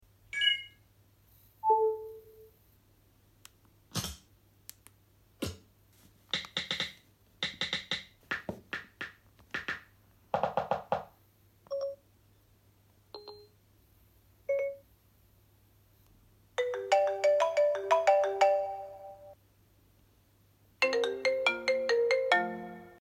iOS 6 vs. iOS 10 sound effects free download
iOS 6 vs. iOS 10 Sounds